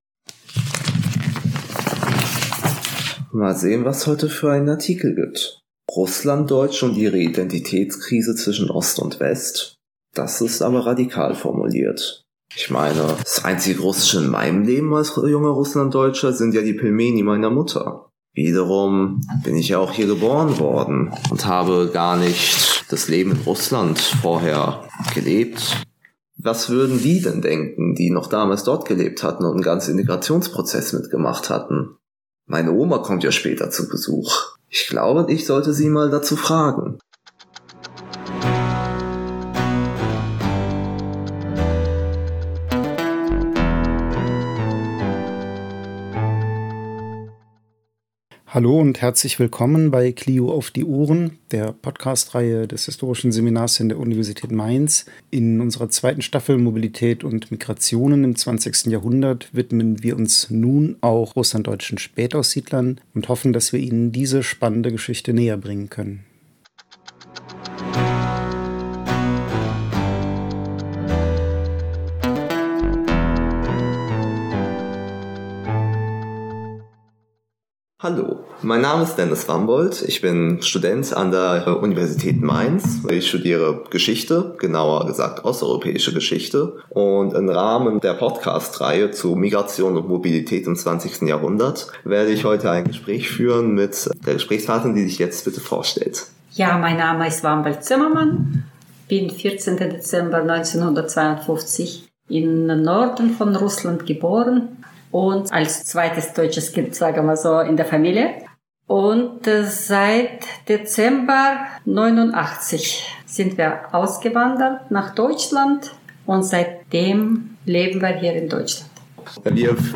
Russlanddeutsche Spätaussiedlerin: Interview